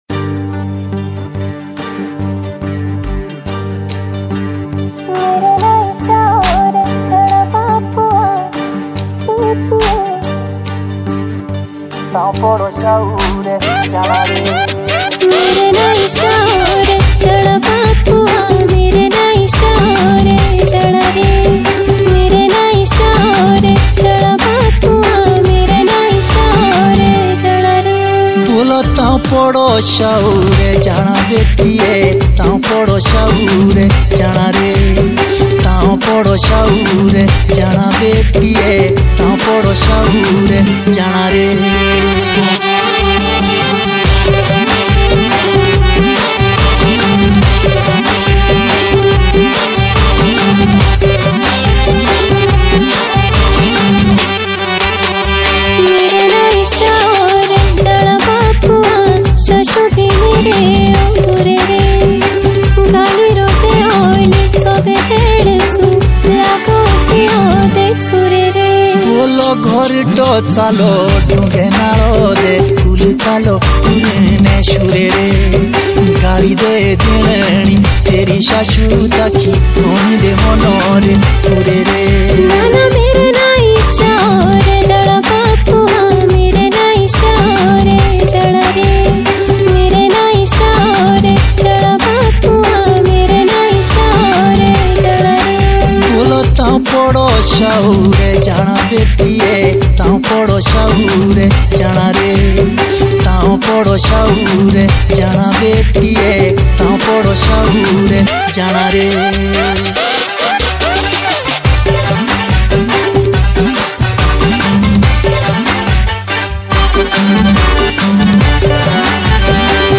Himachali Songs